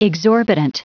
Prononciation du mot exorbitant en anglais (fichier audio)
Prononciation du mot : exorbitant